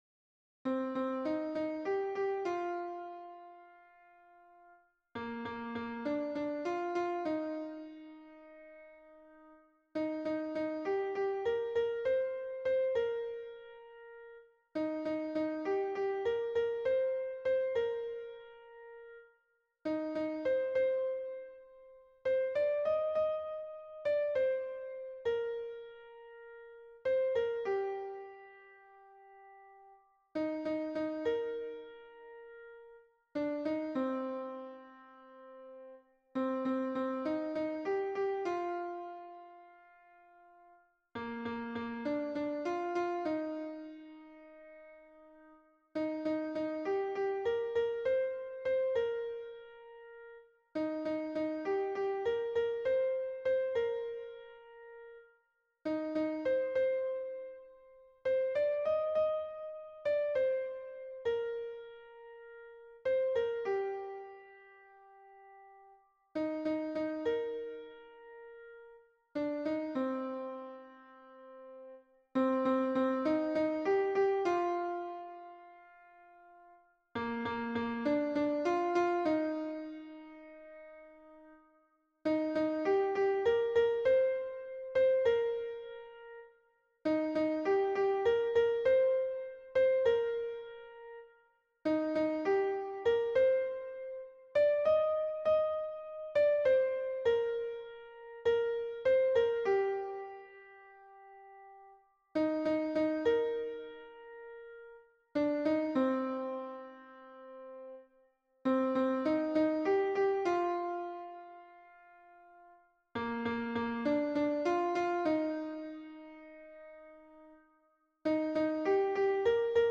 MP3 version piano - 3 voix (pour la chorale)
Soprano